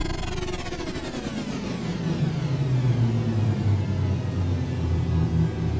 shuttle_stop.ogg